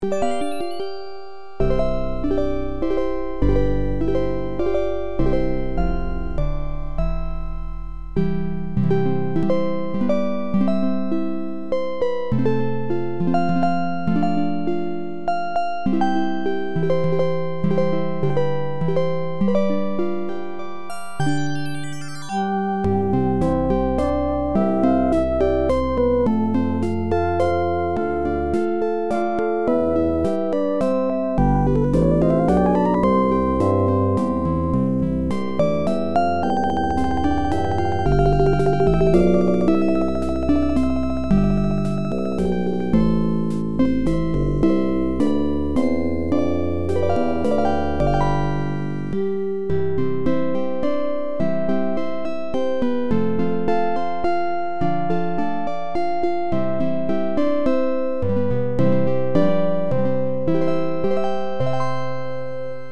A jazzed-up arrangement of